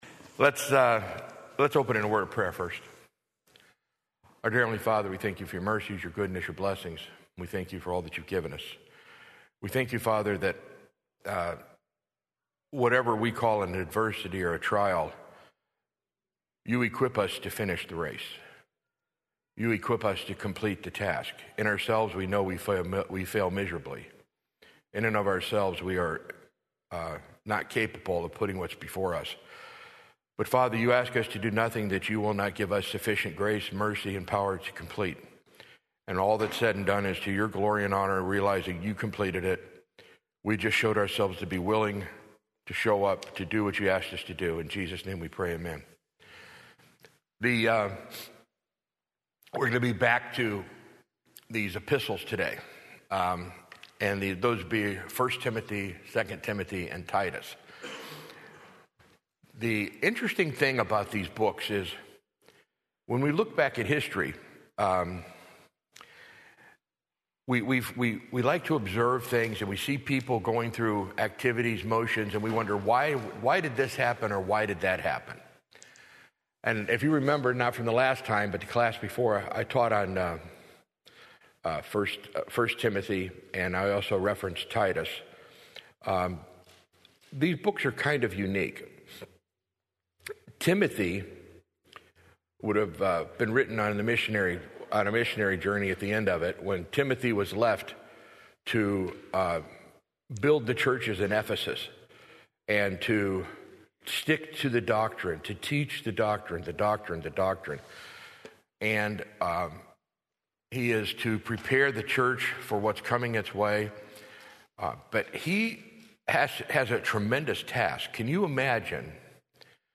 Sunday School - 03/08/2026